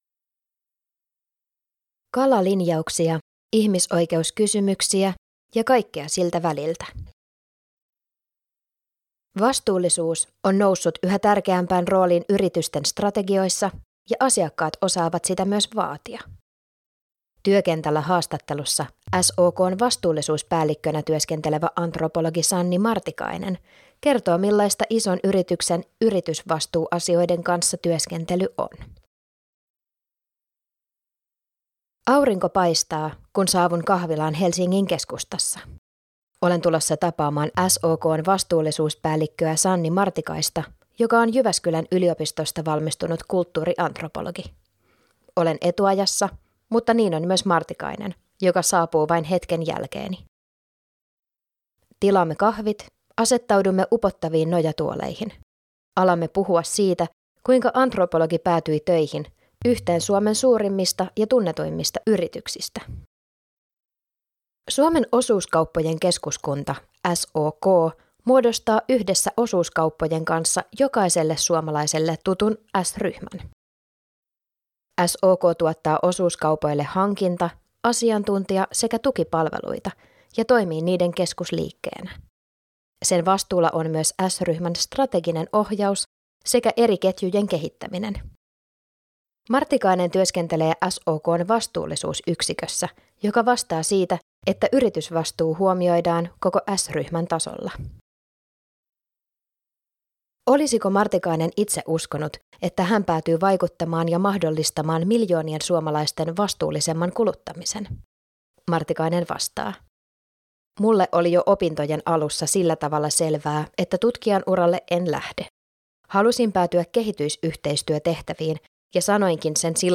Vastuullisuus on noussut yhä tärkeämpään rooliin yritysten strategioissa ja asiakkaat osaavat sitä myös vaatia. Työkentällä-haastattelussa